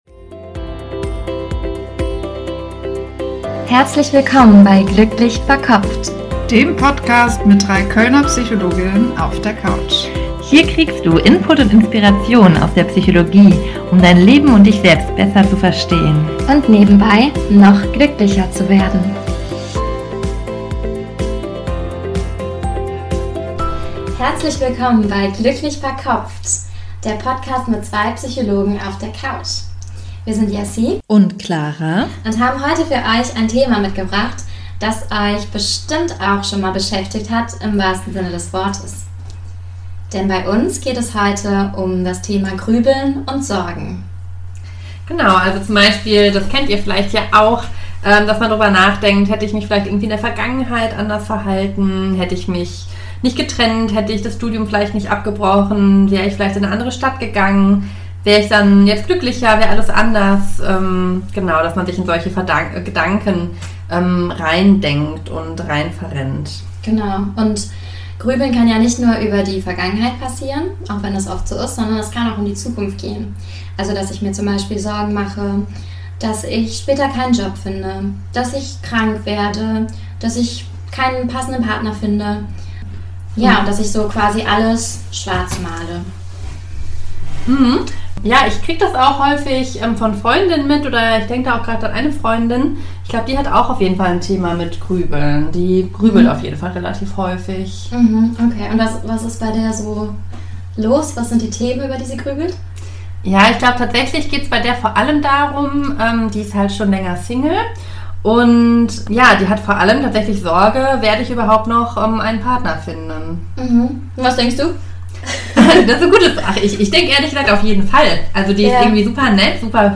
Der Podcast mit Psychologinnen auf der Couch Hier geht es zur Selbstwert-Membership! Manchmal fällt es schwer, uns von Sorgen und negativen Gedanken zu lösen.